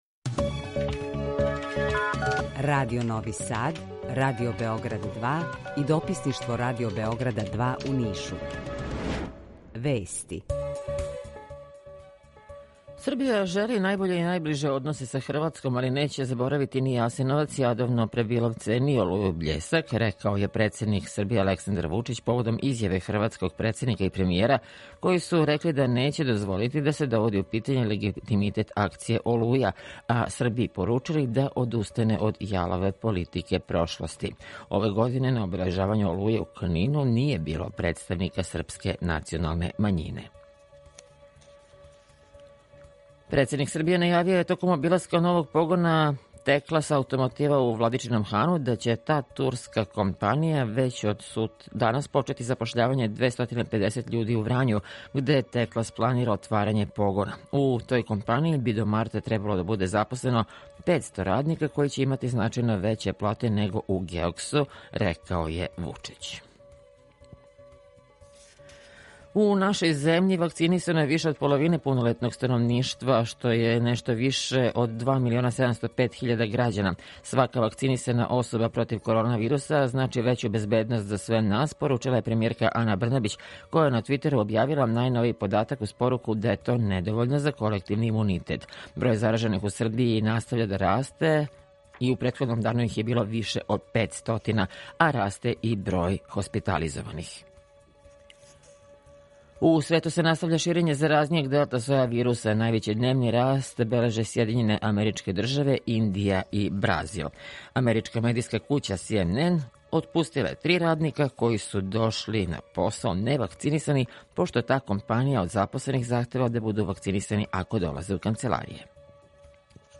Јутарњи програм из три студија
Укључење Радија Републике Српске
У два сата, ту је и добра музика, другачија у односу на остале радио-станице.